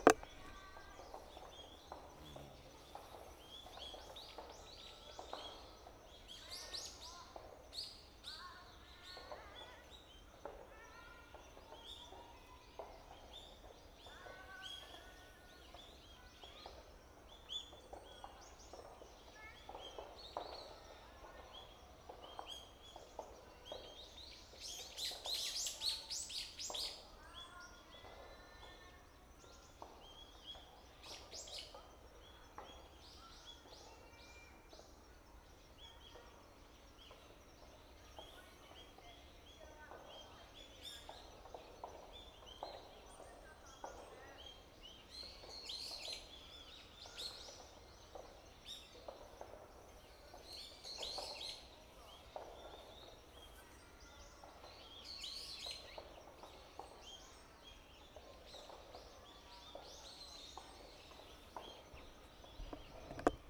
清瀬松山緑地保全地域で野鳥の録音、H2essentialとの録り比べ
清瀬松山緑地保全地域の東側入り口。
録音したファイルを、本体でノーマライズしました。
H2essential MS内蔵マイク指向性120°＋
ZOOM　ヘアリーウィンドスクリーン WSH-2e